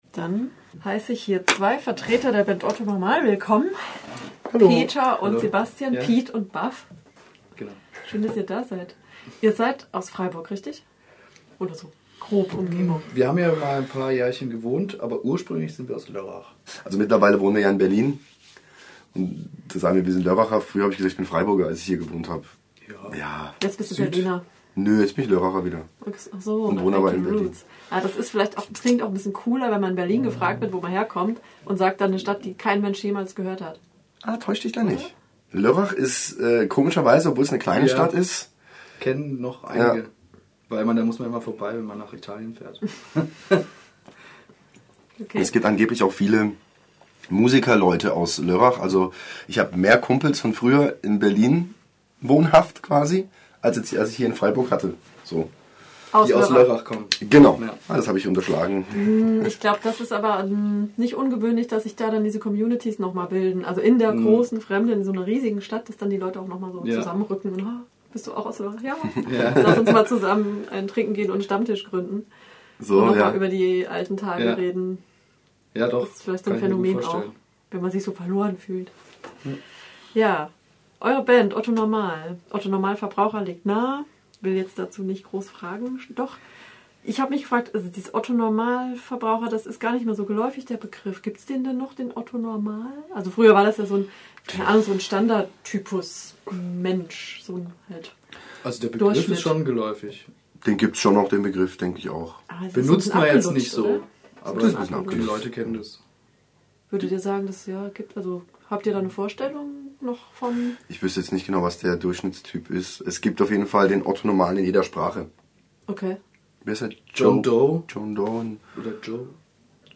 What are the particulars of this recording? im Interview und mit zwei Live-Stücken im RDL-Studio.